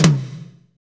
TOM F S H1FR.wav